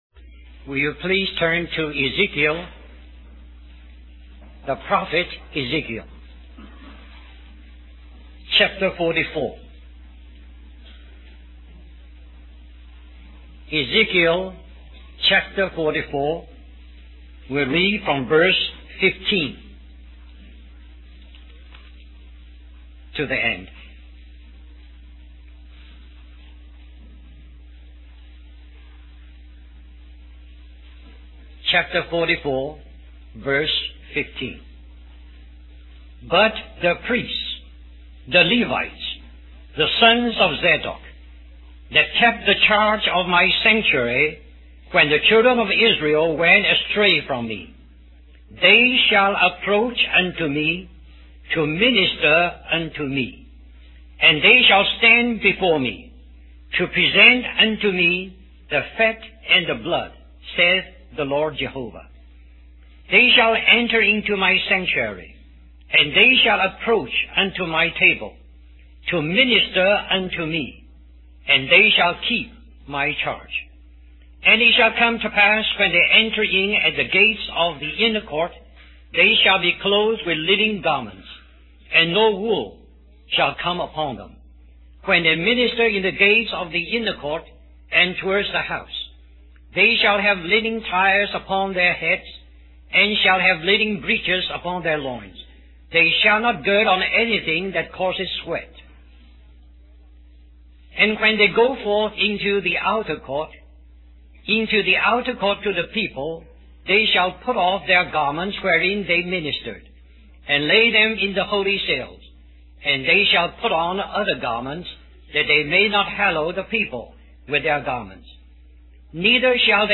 1990 Harvey Cedars Conference Stream or download mp3 Summary Most Christians see ministry as that which is done for the benefit of man for his good. Indeed ministry includes this, but true ministry is ministry to the Lord in the inner court. When we are in His presence ministering to Him, we will also receive what we need to minister to men in the outer court.